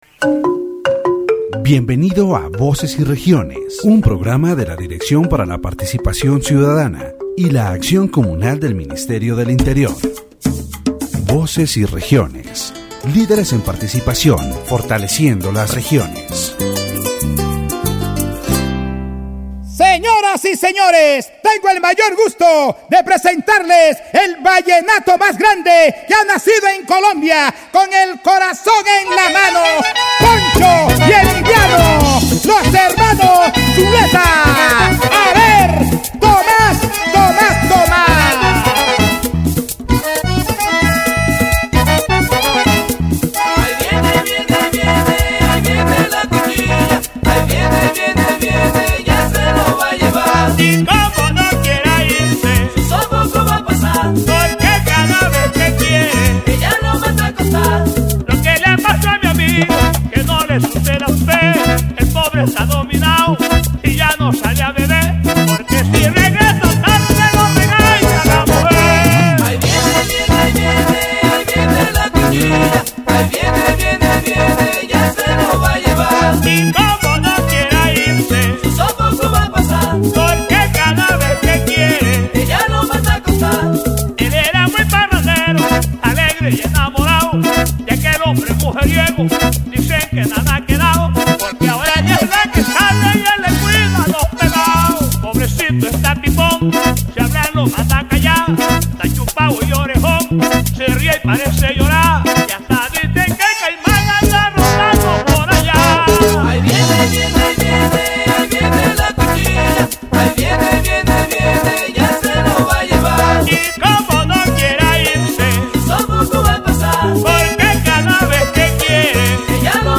In this episode of Voces y Regiones, led by the Ministry of the Interior and broadcast by the Francisco José de Caldas University radio station